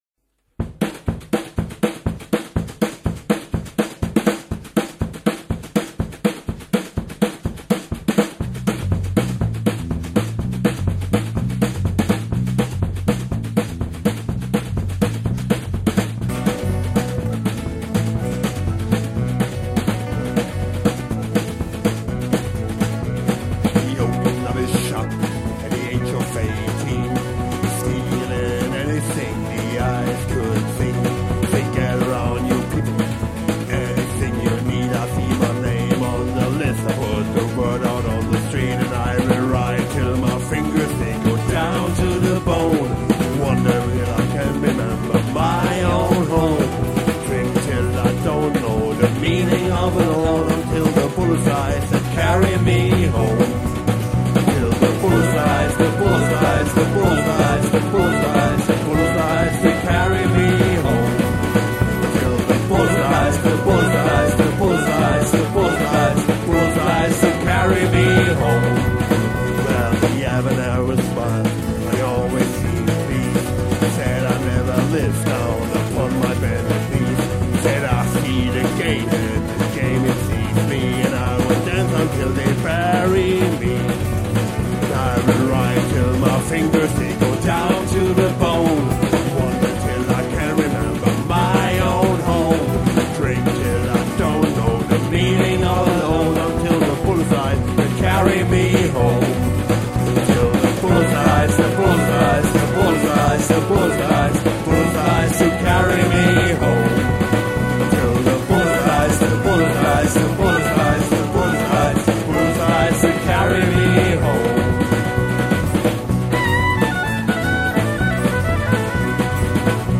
Schlagzeug, Percussion, Gesang
Piano, Orgel, Pedal Steel